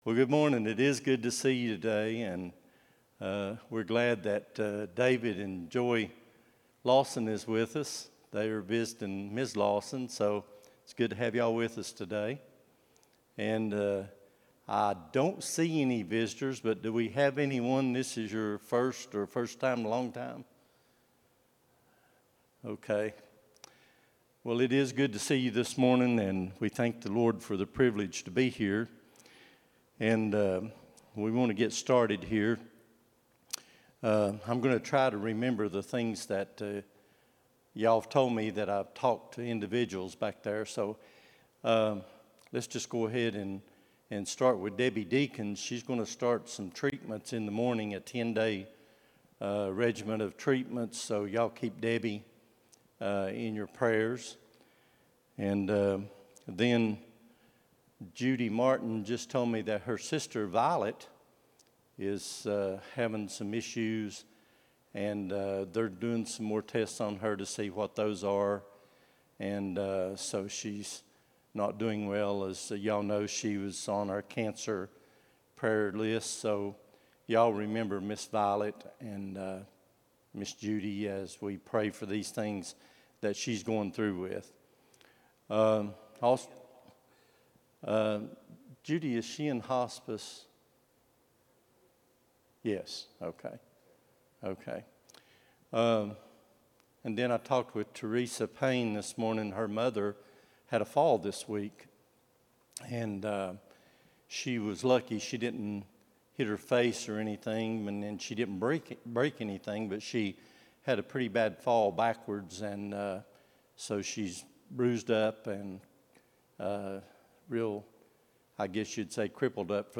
11-17-24 Sunday School | Buffalo Ridge Baptist Church